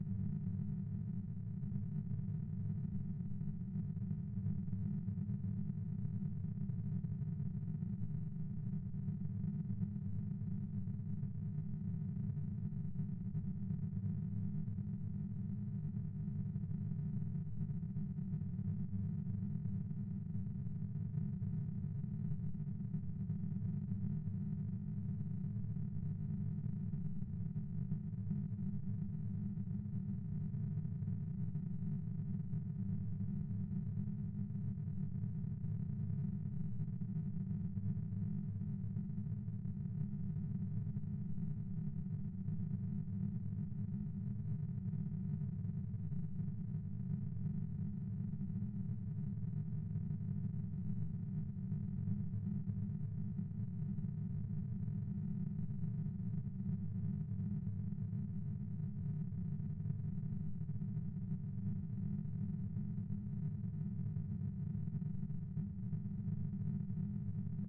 Washing machine - Flex speed change trial